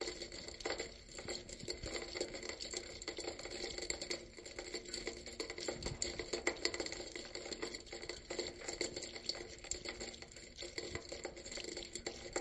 描述：用MKH60录制到SoundDevices 744T高清录音机。我把麦克风对准一个排水管的孔和下面的小水池。
标签： 排水管 光雨
声道立体声